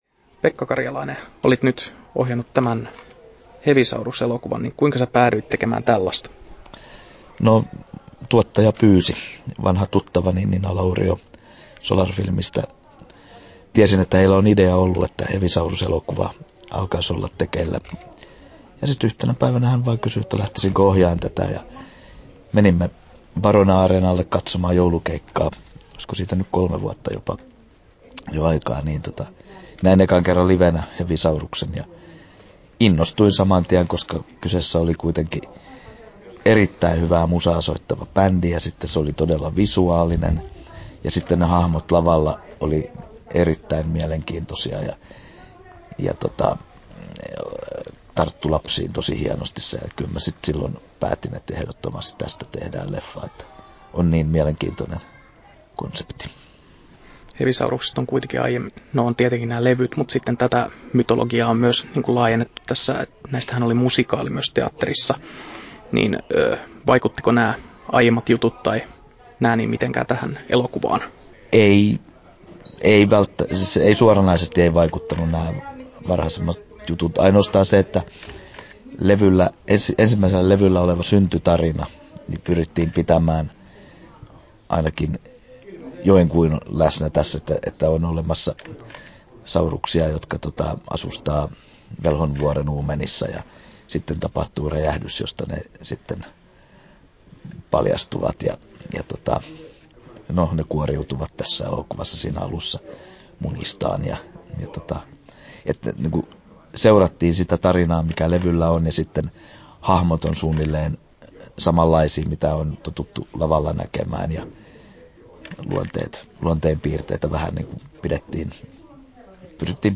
Hevisaurus-elokuva • Haastattelut